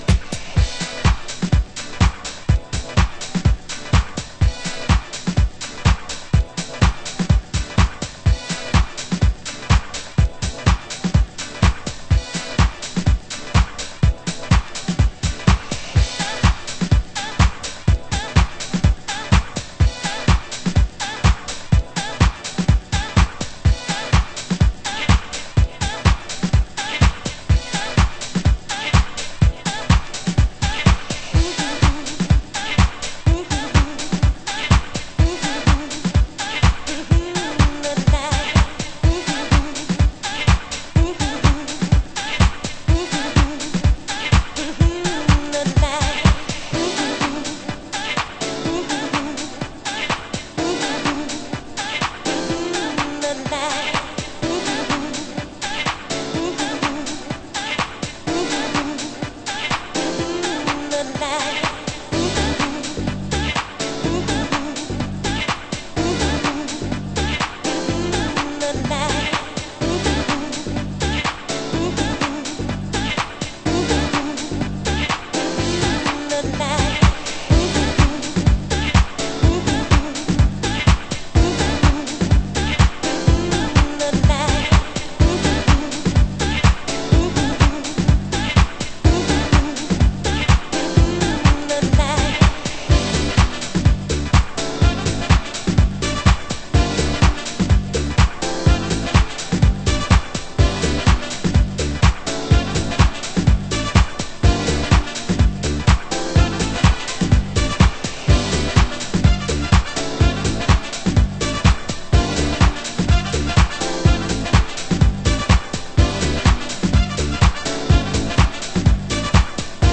盤質：チリパチノイズ有